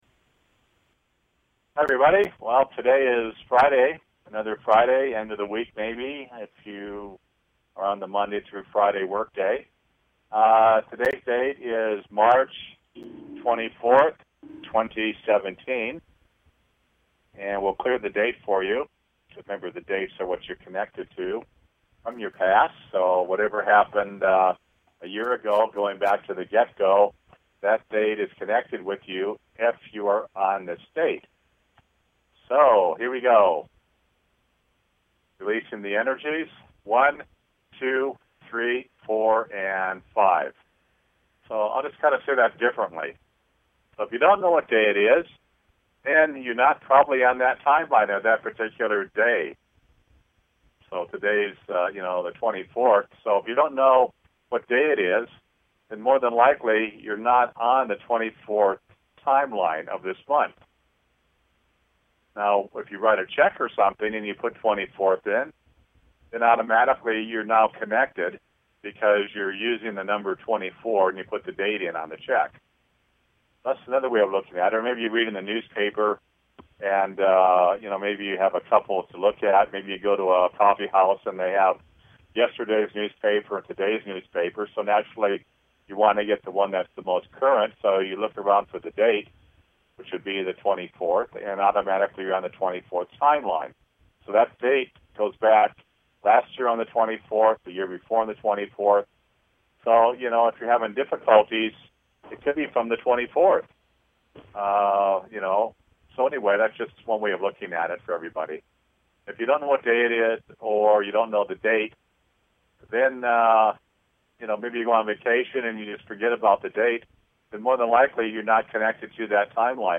Tune into the Creation Lightship Healing BBS Radio Show to experience this Divine Lightship and its teachings.